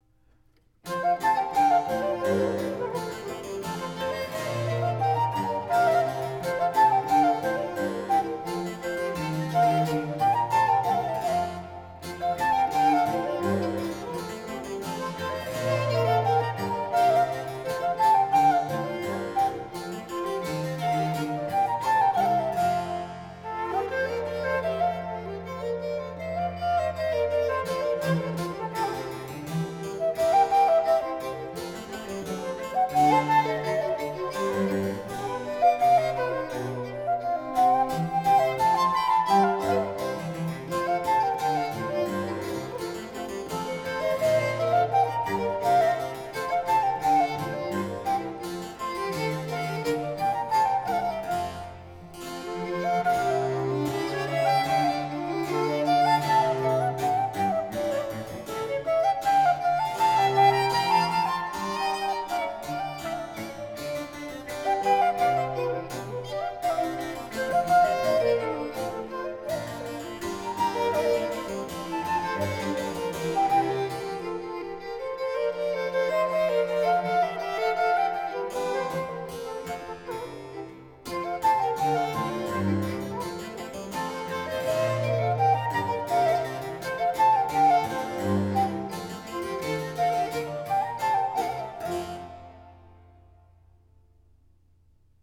notes and musings of a harpsichordist